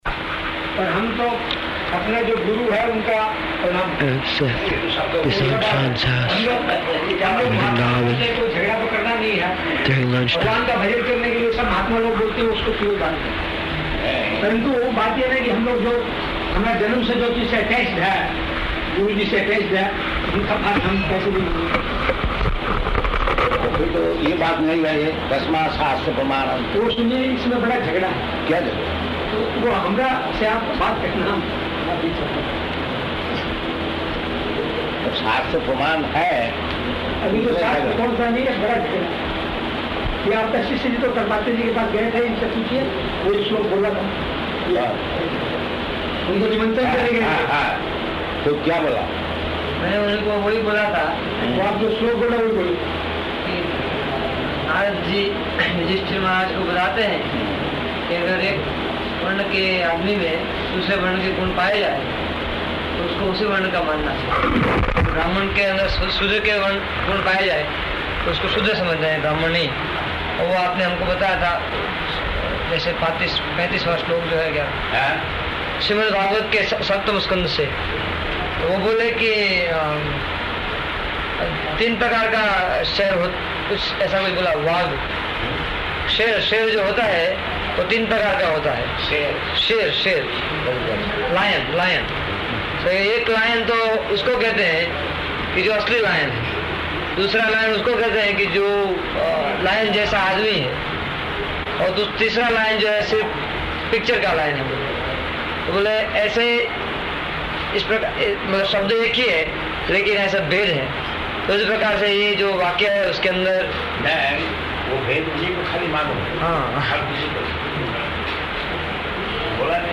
Conversation in Hindi
Type: Conversation
Location: Vṛndāvana